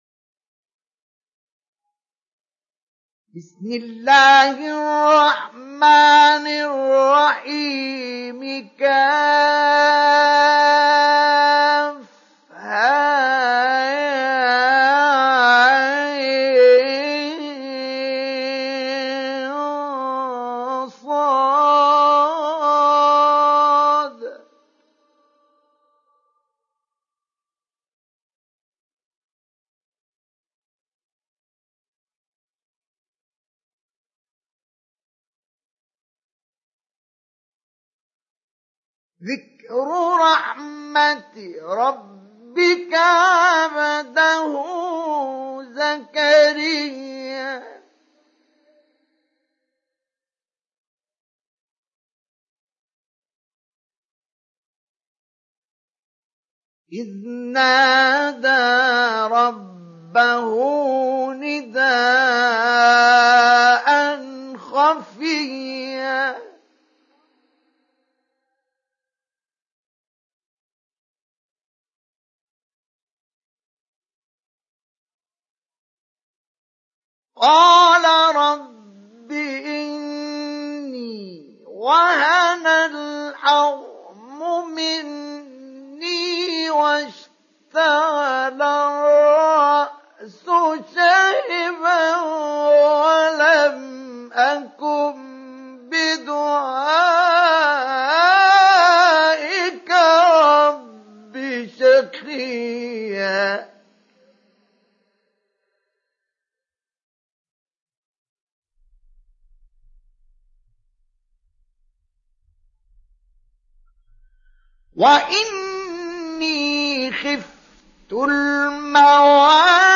دانلود سوره مريم مصطفى إسماعيل مجود